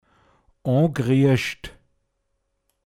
pinzgauer mundart
o(n)griascht angerührt, empfindlich